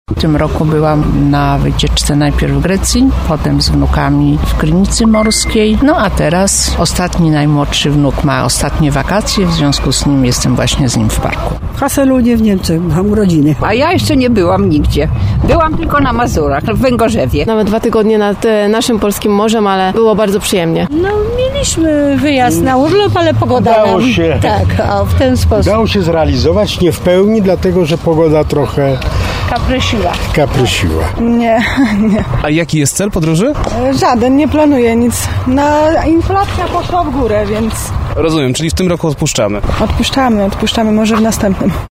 Sezon wakacyjny zbliża się do końca. Zapytaliśmy warszawiaków czy udało im się zrealizować tegoroczne plany urlopowe: